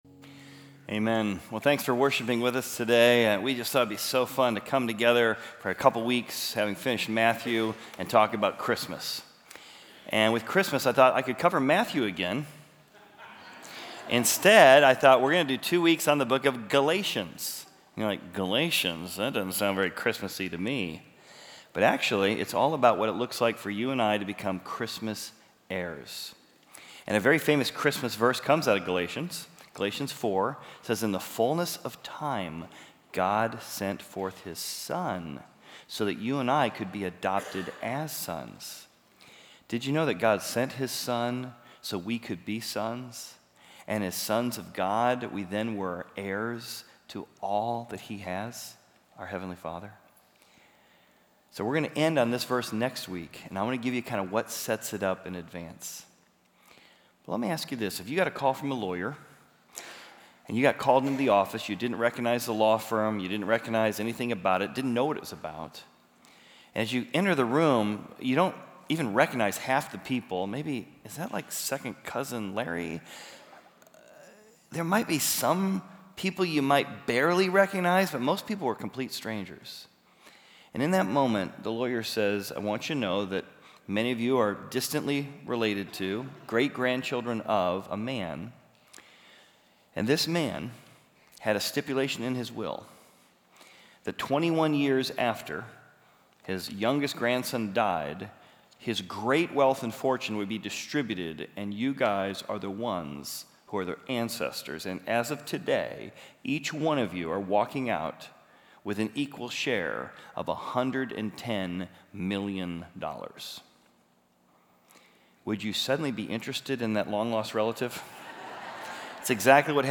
Equipping Service / Galatians: Christmas Heirs / Generous Sonship